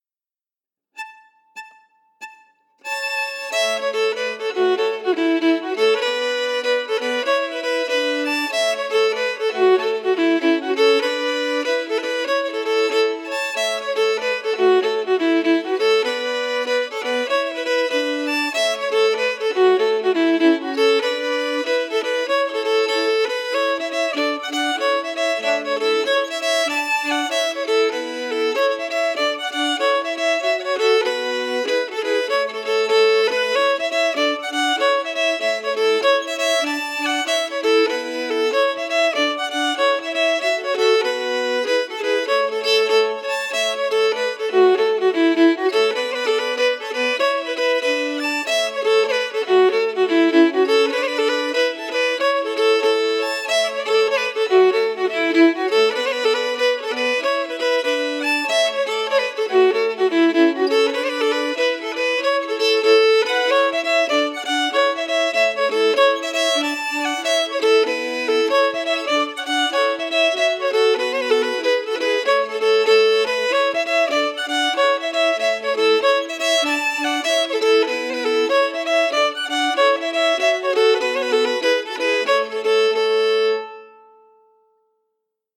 Key: A
Form: Jig
Melody emphasis
Region: Ireland